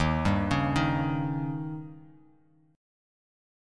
D#7 Chord
Listen to D#7 strummed